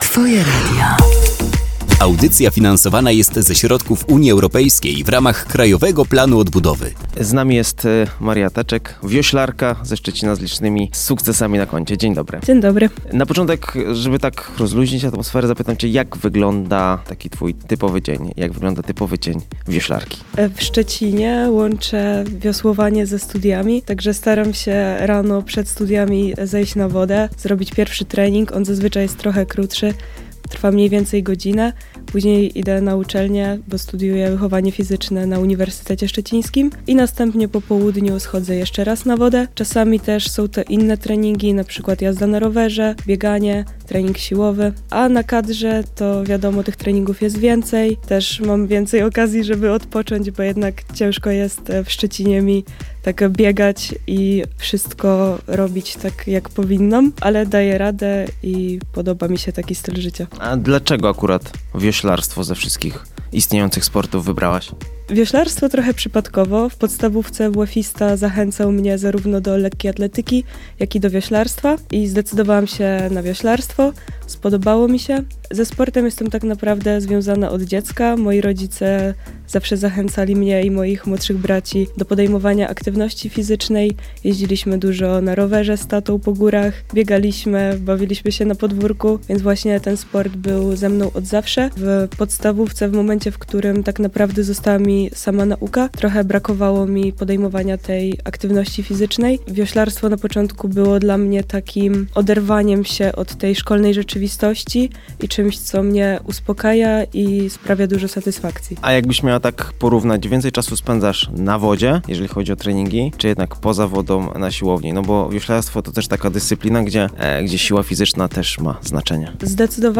Wywiad, który mogliście usłyszeć na antenie Twojego Radia, jest już dostępny w formie podcastu!